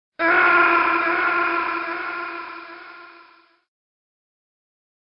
Metal Gear Solid Snake Death Scream Sound Effect